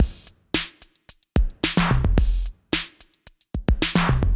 Index of /musicradar/80s-heat-samples/110bpm